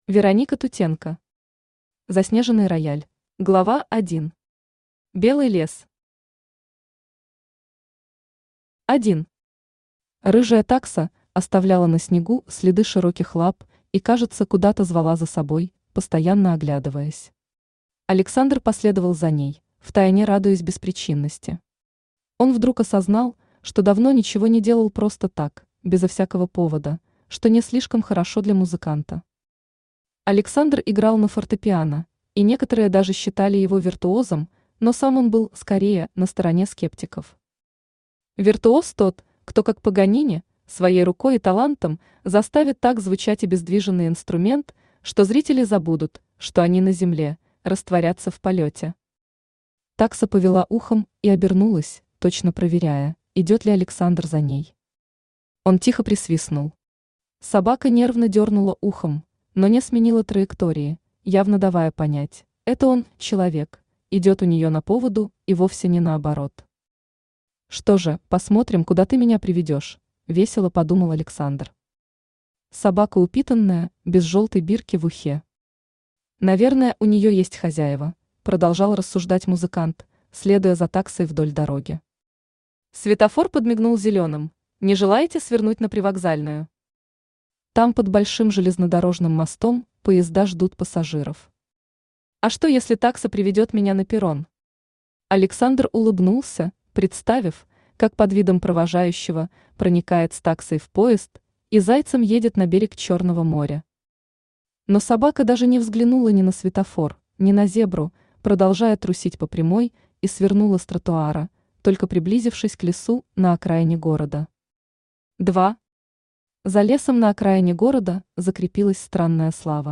Аудиокнига Заснеженный рояль | Библиотека аудиокниг